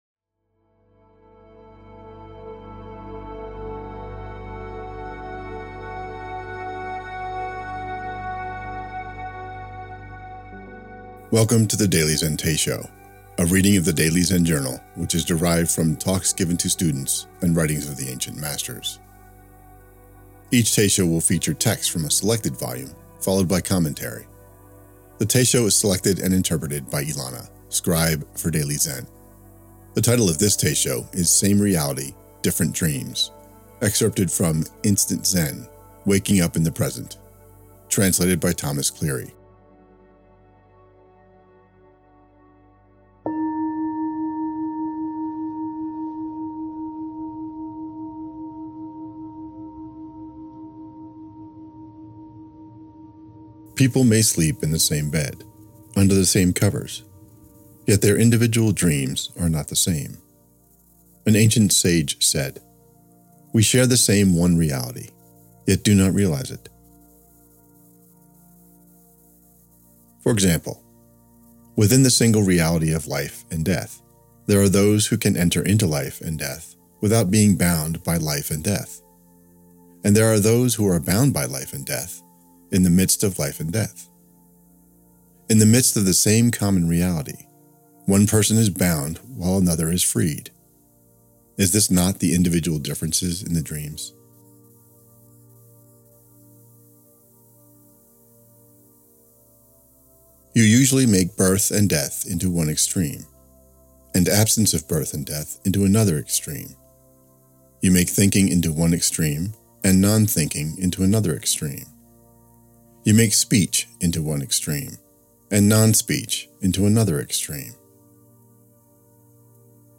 Teisho